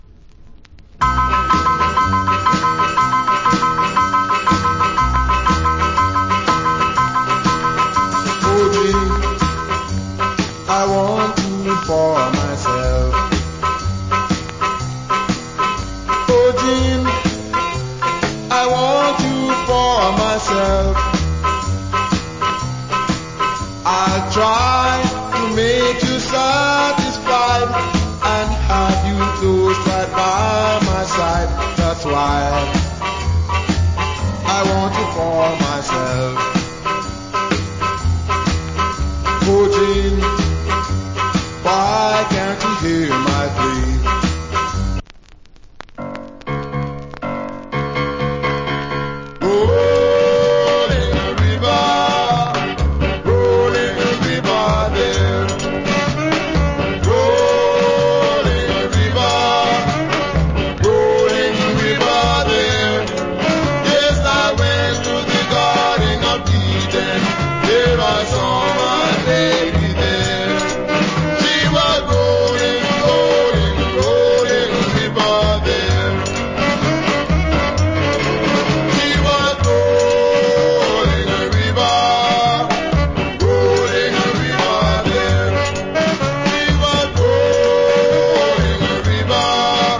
Nice UK Ska Vocal.